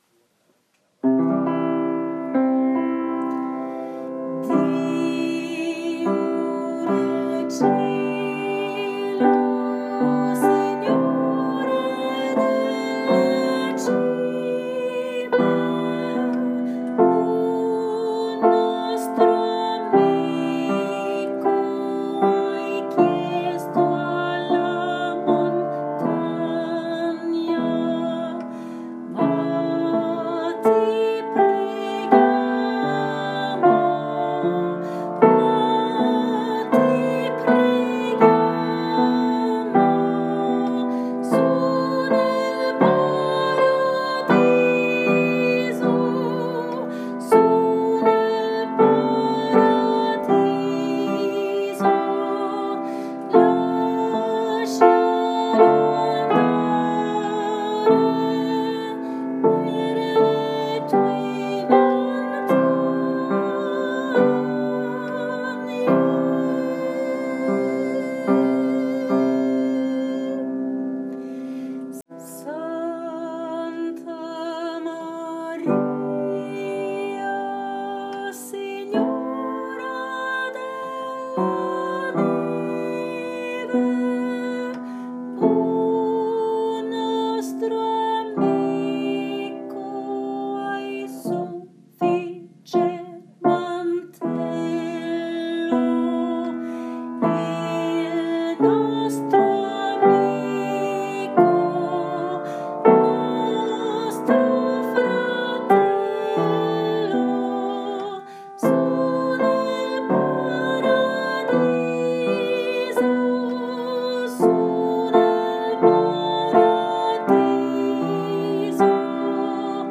Sopran
SignoreDelleCimeSopran.m4a